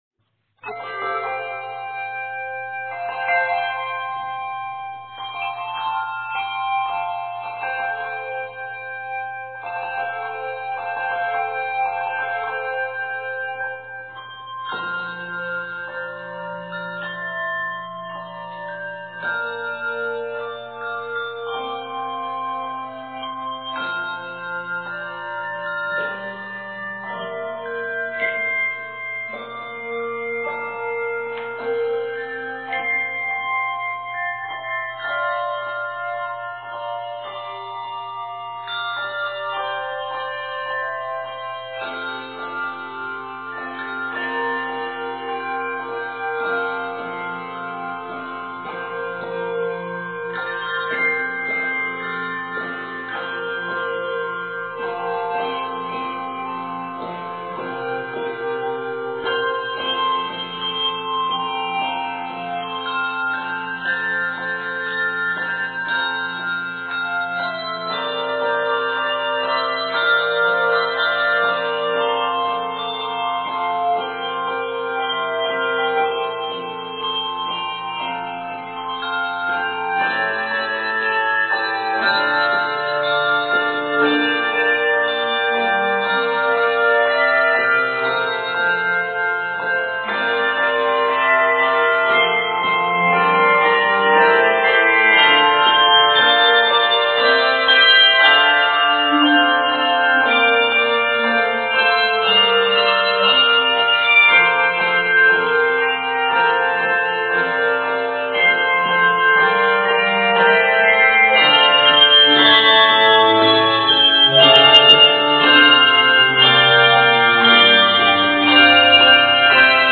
set in F Major
Octaves: 3-6